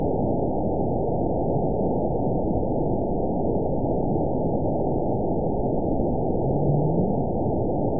event 917202 date 03/23/23 time 17:25:35 GMT (2 years, 1 month ago) score 9.06 location TSS-AB05 detected by nrw target species NRW annotations +NRW Spectrogram: Frequency (kHz) vs. Time (s) audio not available .wav